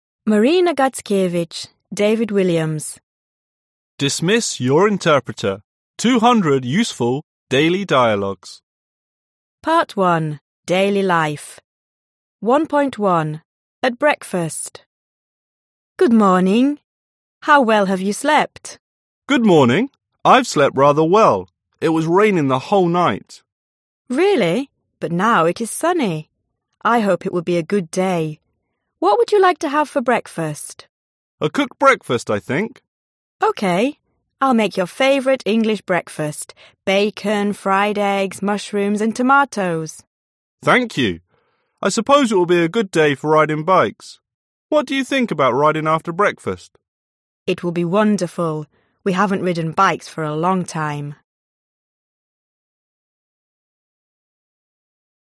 Аудиокнига Английский без переводчика. 200 диалогов | Библиотека аудиокниг